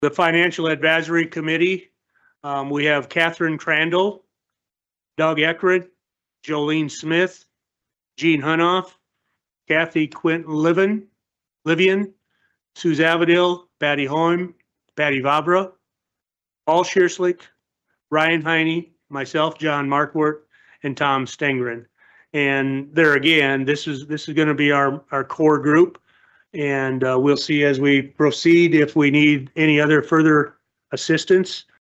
Yankton County Commission Chair John Marquardt laid out the names of the committee members.
The county commission announced the committee at Tuesday’s commission meeting.